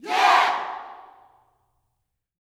YEAH  11.wav